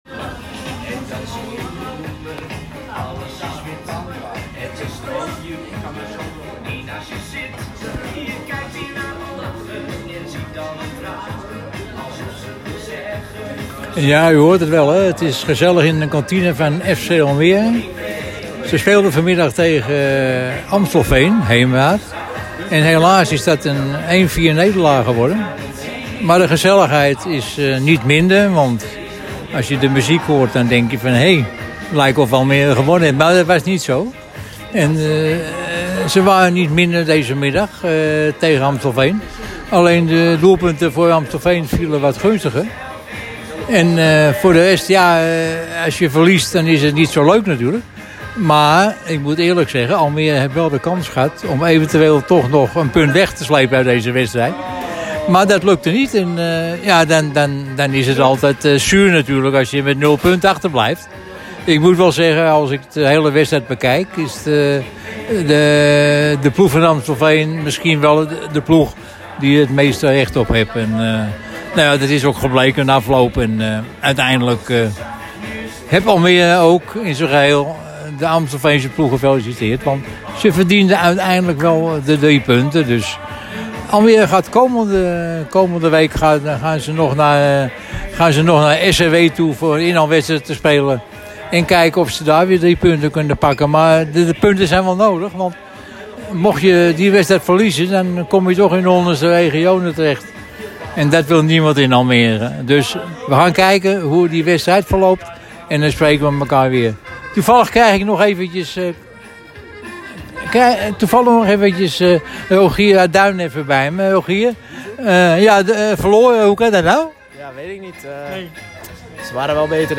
Het was weer gezellig in de kantiine tijdens dit interview